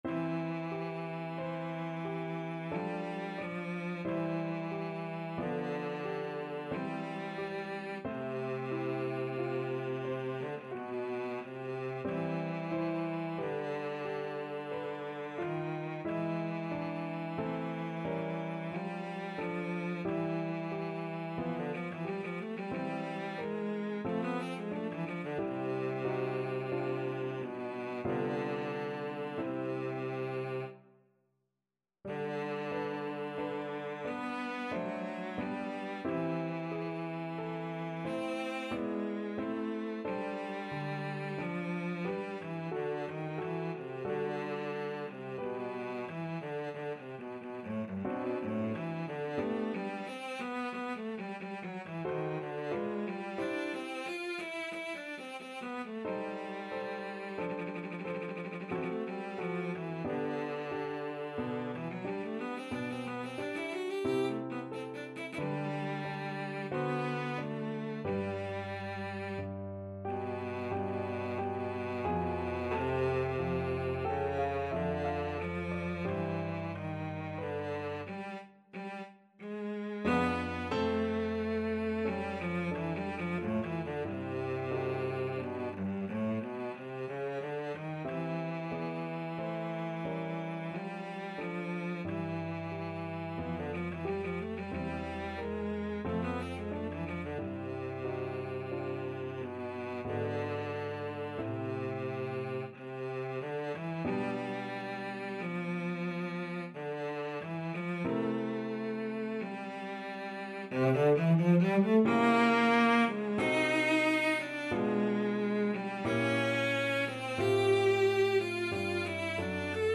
Classical Spohr, Louis Clarinet Concerto No.1, Op.26, Second Movement Cello version
Cello
C major (Sounding Pitch) (View more C major Music for Cello )
3/4 (View more 3/4 Music)
Adagio =45
Classical (View more Classical Cello Music)